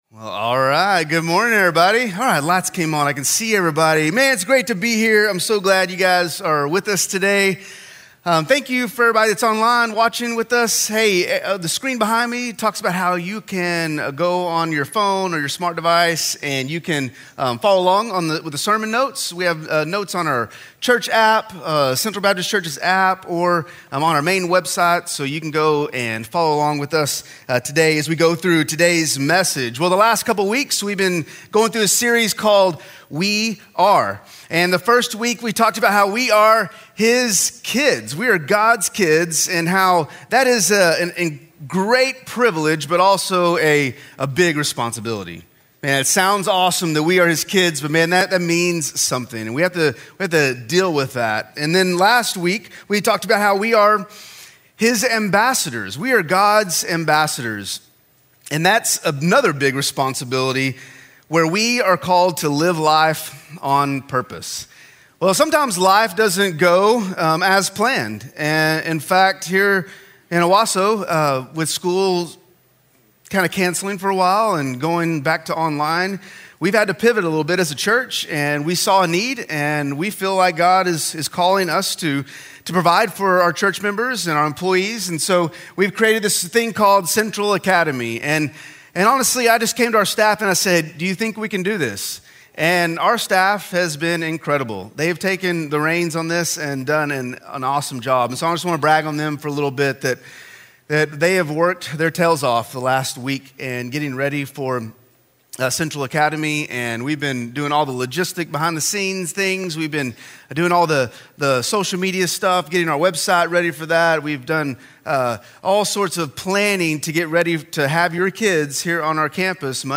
Join us for August 16th, 2020 10:45am service at CBC.